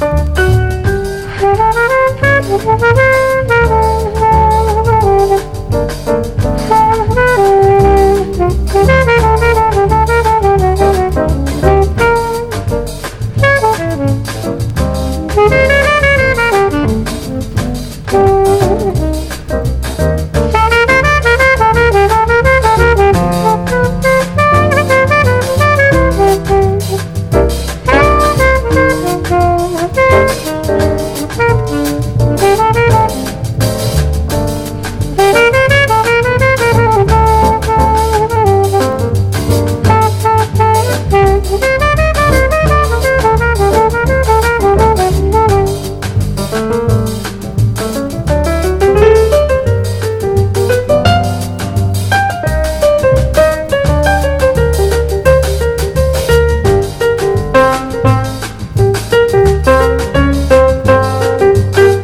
国産90'Sファンキー・レイヴ/トランス・レア盤！
アタマからラストまでフロアをブチあげるハイ・テンションなバッキバキのレイヴ・サウンドです！